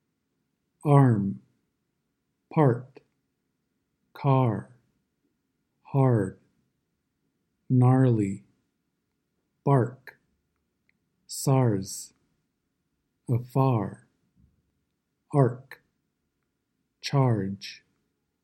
Lesson 5 – “R” vowels /ɝ/, /ɚ/, /ɑr/, /ɜr/, /ɪr/, /ɔr/ – American English Pronunciation
The /ɑr/ sound